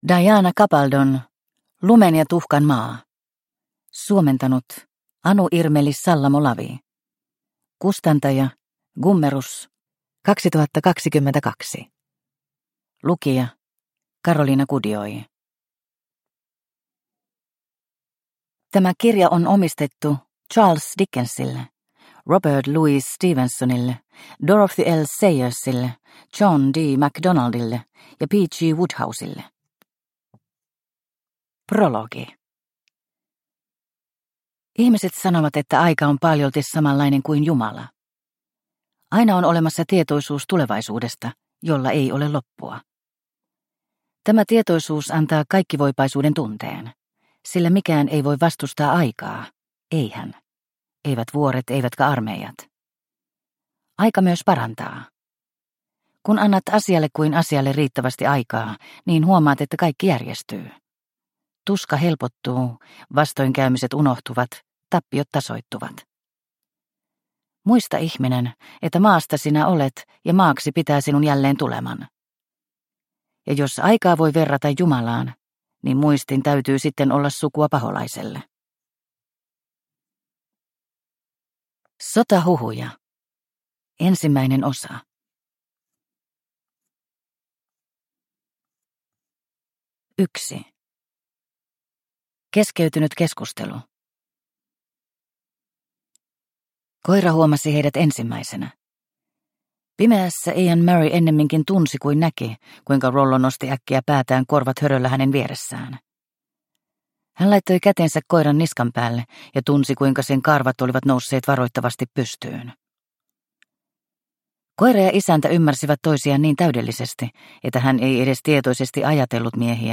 Lumen ja tuhkan maa – Ljudbok – Laddas ner